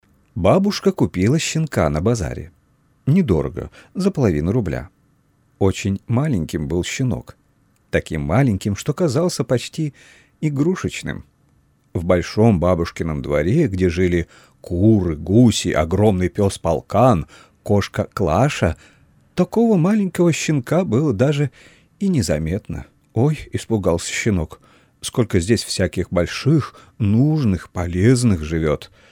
Обладаю мягким, неагрессивным баритоном, подходящим для множества задач.
внешняя звуковая карта M-AUDIO микрофон AUDIO-TECHNICA AT-4033 поп-фильтр, кабинет